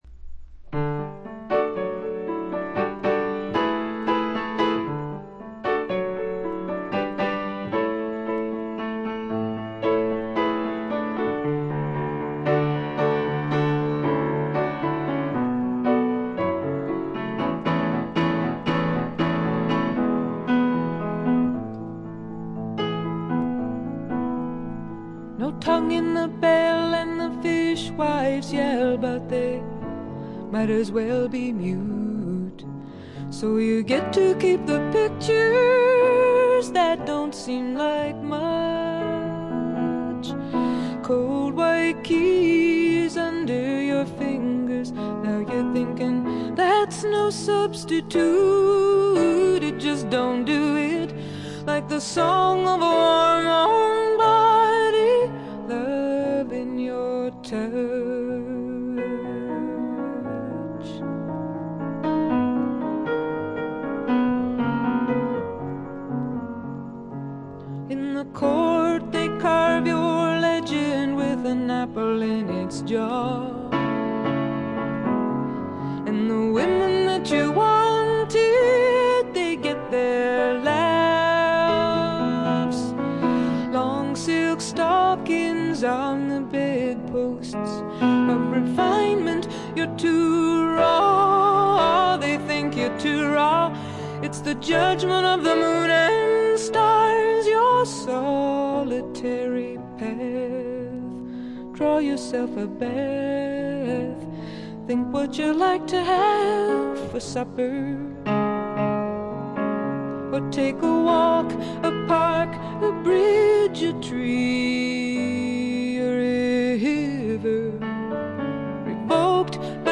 わずかなノイズ感のみ。
試聴曲は現品からの取り込み音源です。
Recorded At - A&M Studios